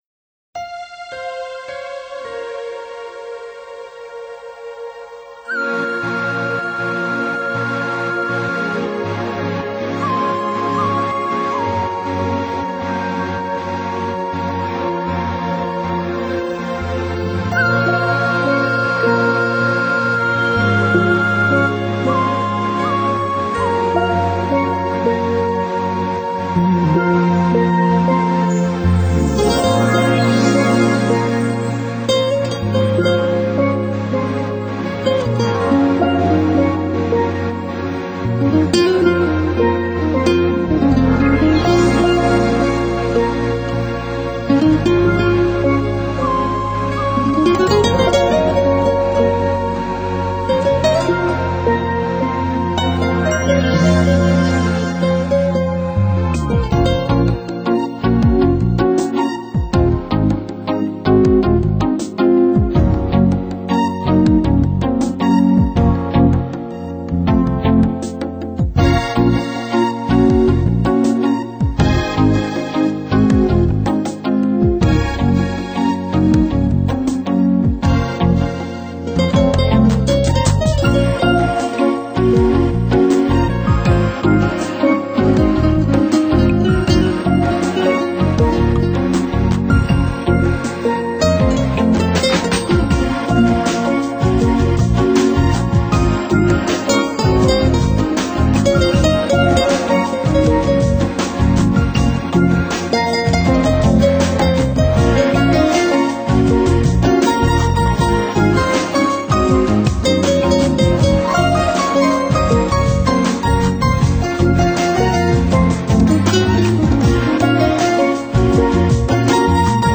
专辑类型：热带民族音乐大融合 附注说明：放松时聆赏